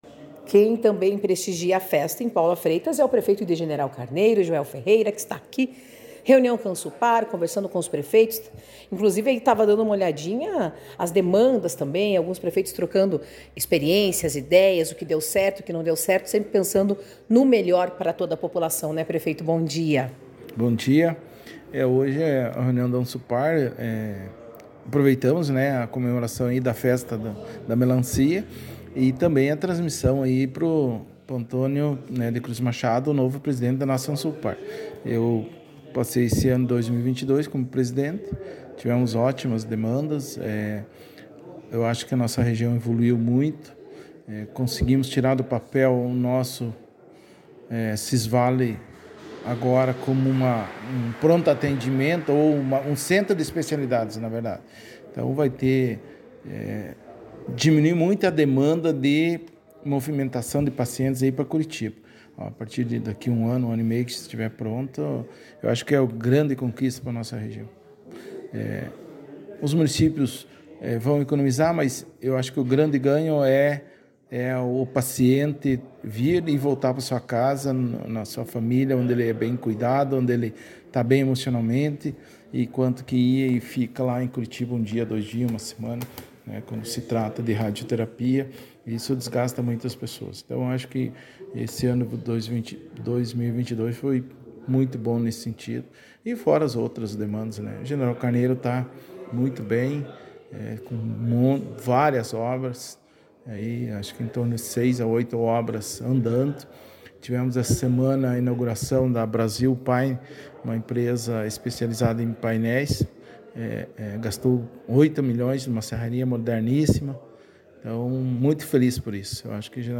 Prefeito de General Carneiro, Joel Martins Ferreira